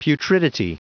Prononciation du mot putridity en anglais (fichier audio)
Prononciation du mot : putridity